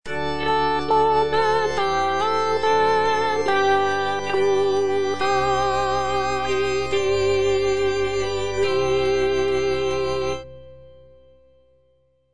M.A. CHARPENTIER - LE RENIEMENT DE ST. PIERRE Respondens autem Petrus (soprano II) (Voice with metronome) Ads stop: auto-stop
It is an oratorio based on the biblical story of Saint Peter's denial of Jesus Christ.